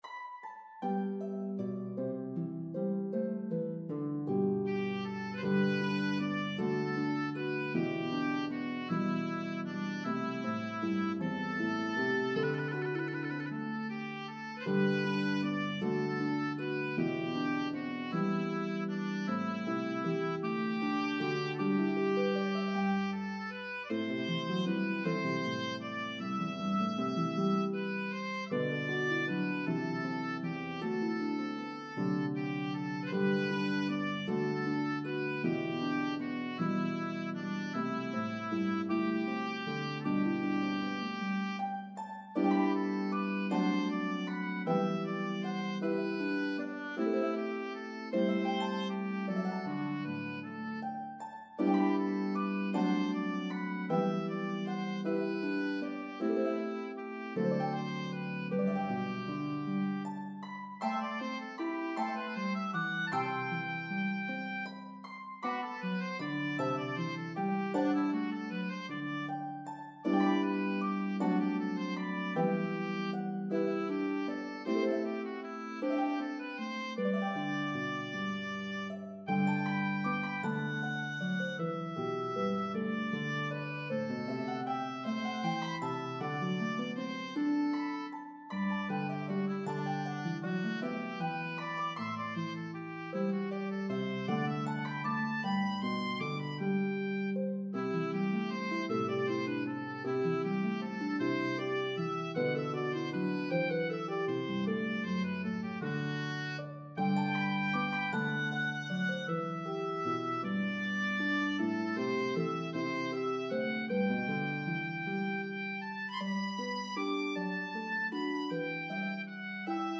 Harp and Oboe version